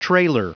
Prononciation du mot trailer en anglais (fichier audio)
Prononciation du mot : trailer